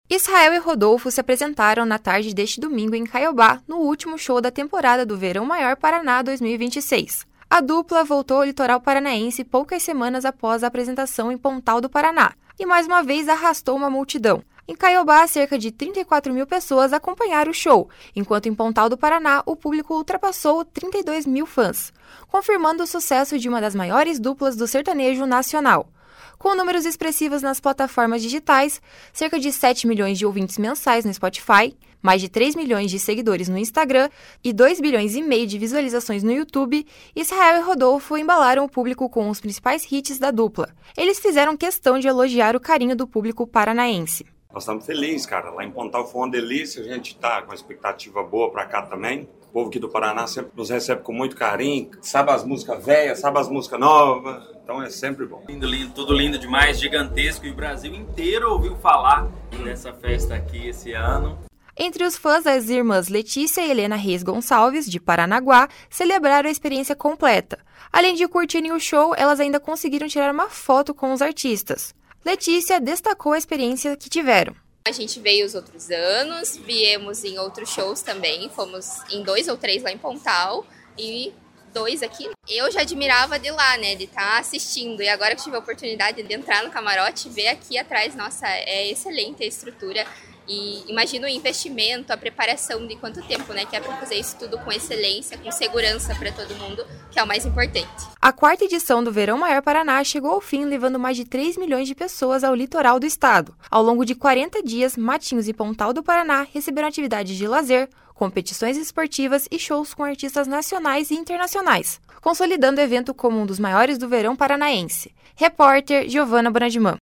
// SONORA ISRAEL E RODOLFO //